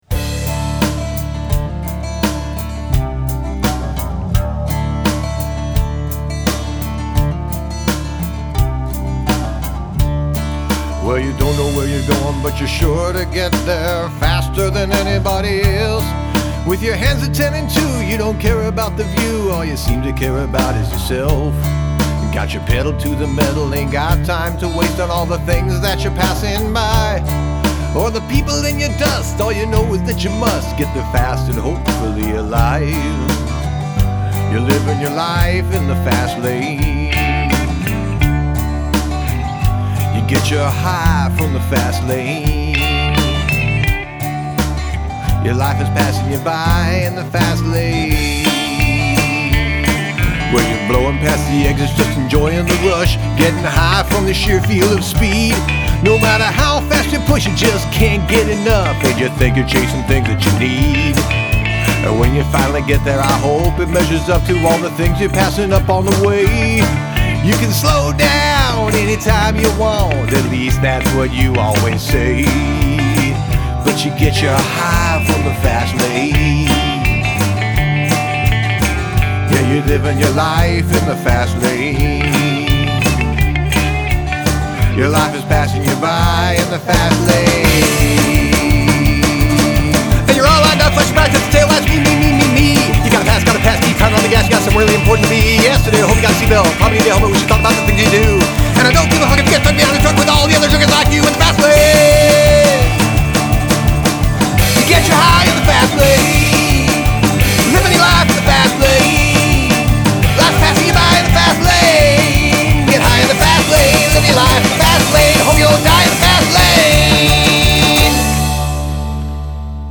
The double-time on here is hilarious.